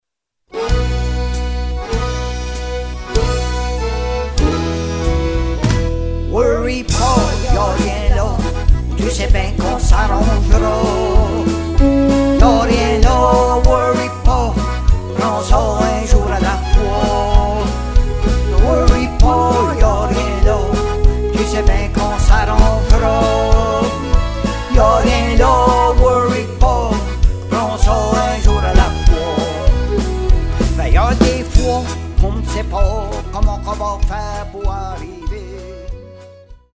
Musique acadien – CD complete – MP3